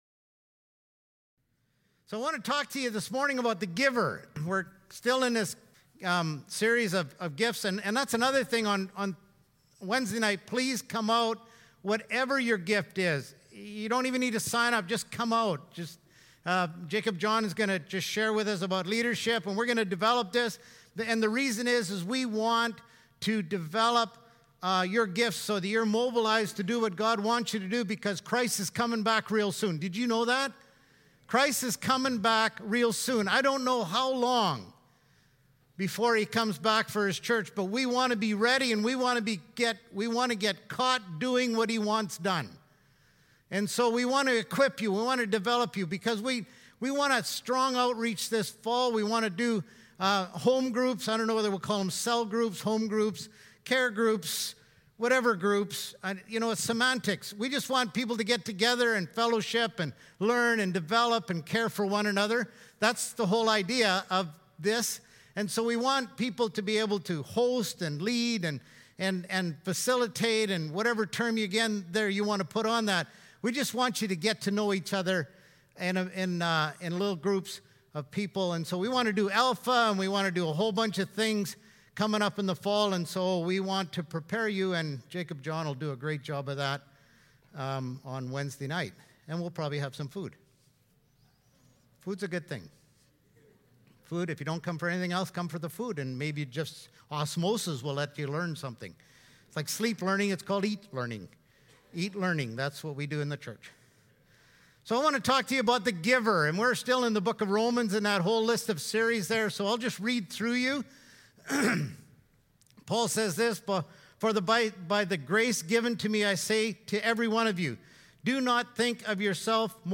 Sermons | Richmond Pentecostal Church